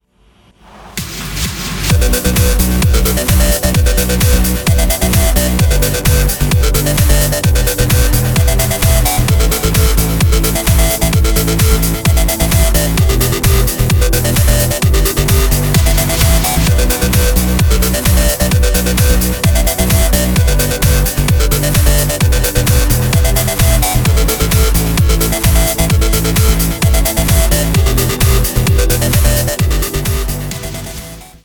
• Качество: 191, Stereo
progressive house
Trance
Завораживающий транс